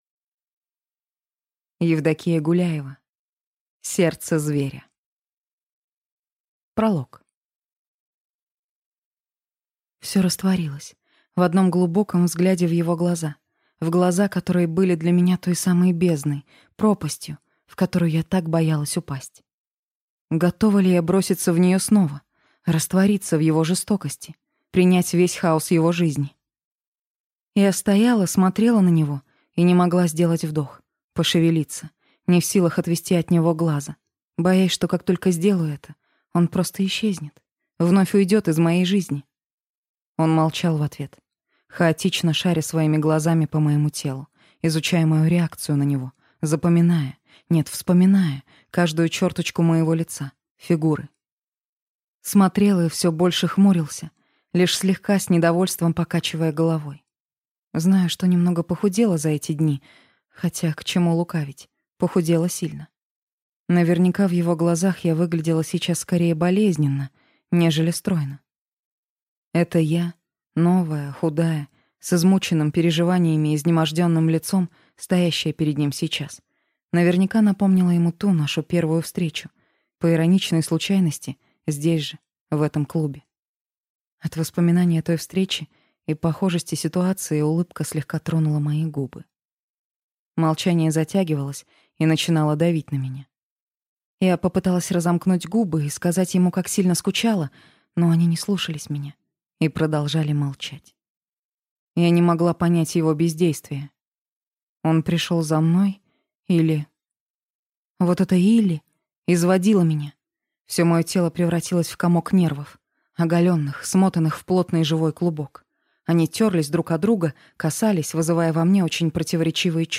Аудиокнига Сердце Зверя | Библиотека аудиокниг